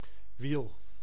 Ääntäminen
IPA: [ʋiːl]